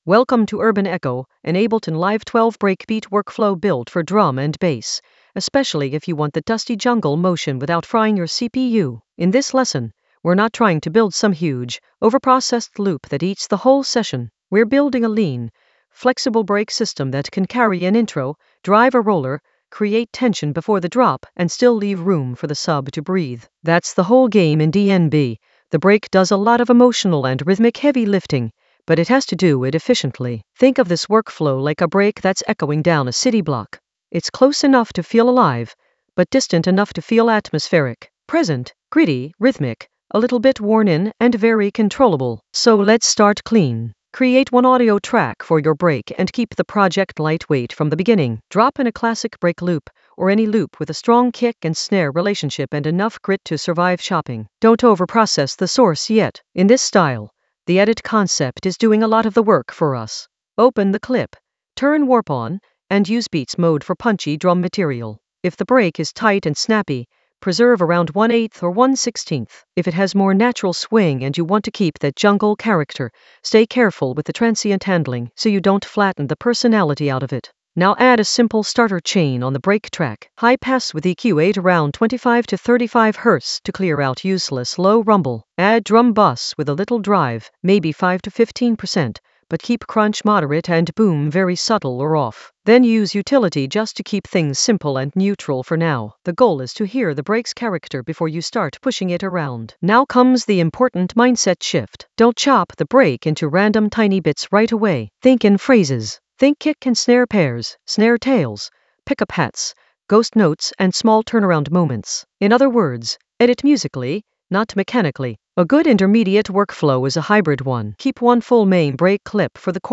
An AI-generated intermediate Ableton lesson focused on Urban Echo Ableton Live 12 breakbeat workflow with minimal CPU load in the Edits area of drum and bass production.
Narrated lesson audio
The voice track includes the tutorial plus extra teacher commentary.